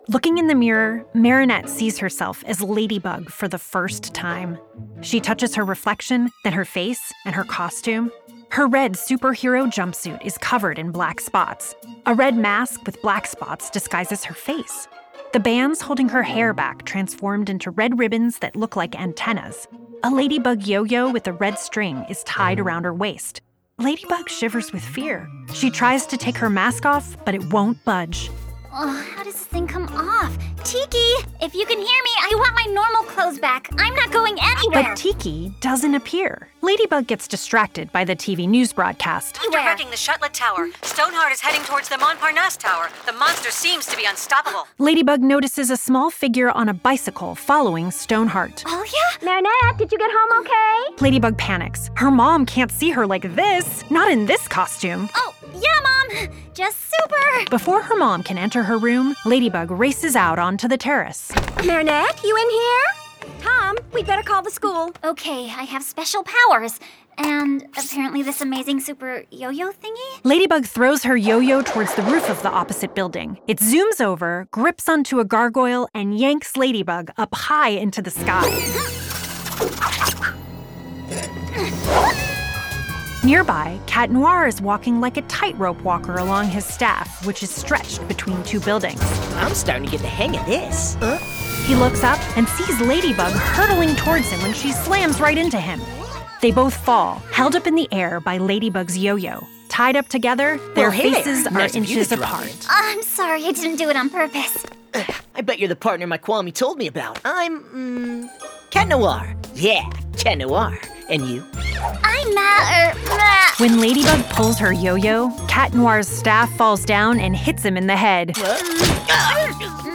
With its friendly, informative, and professional tone of voice, the Miraculous Tonies offer a captivating listening adventure for kids while stimulating their imagination and creativity.